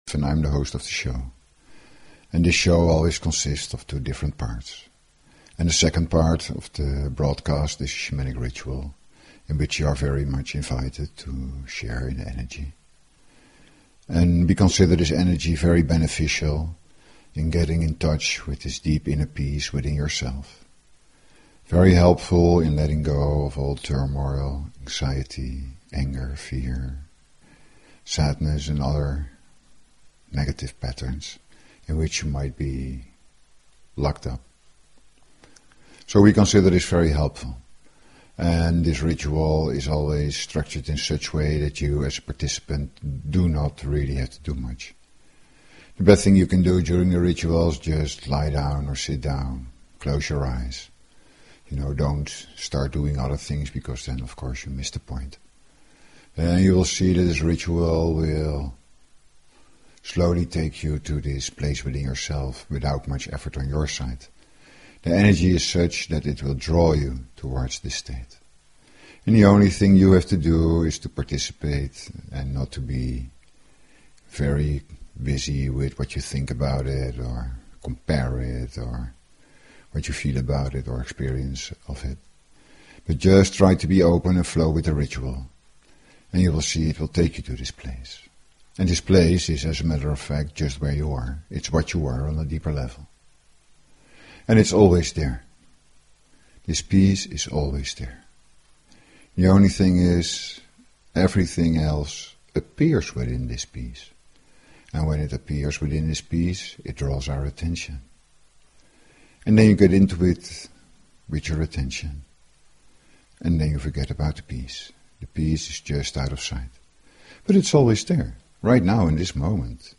Talk Show Episode, Audio Podcast, Shuem_Soul_Experience and Courtesy of BBS Radio on , show guests , about , categorized as
The Meditation-ritual in the second part of the show is to touch this state of inner peace.